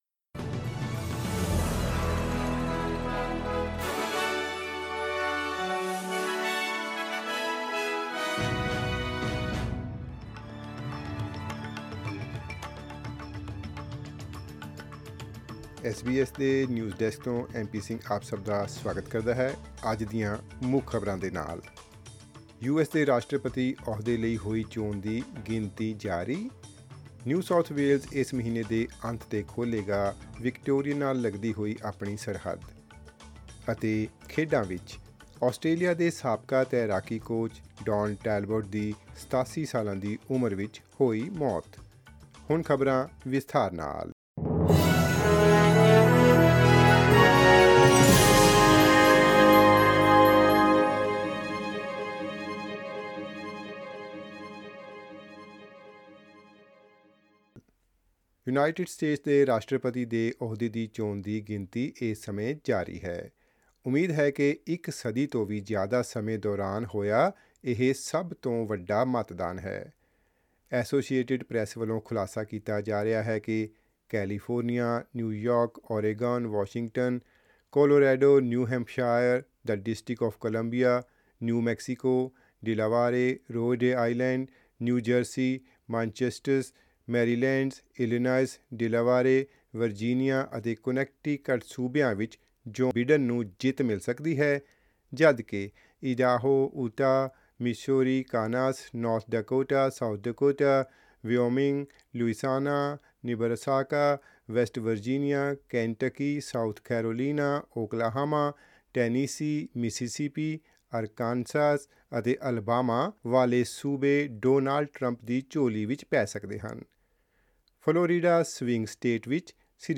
In this bulletin: Counting underway in the United States presidential election; New South Wales to open its border to Victoria later this month; And, in sport, former Australian swimming coach Don Talbot dies aged 87.